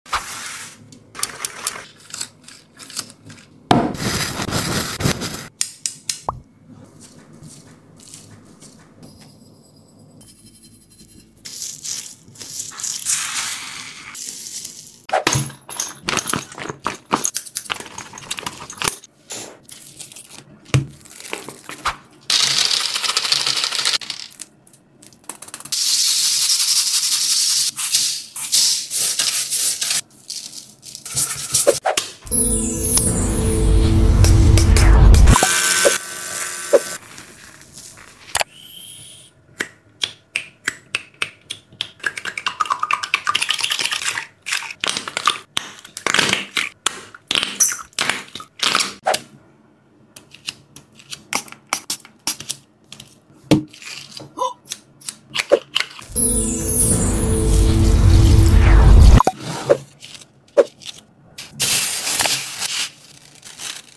FUFU SQUISHY ASMR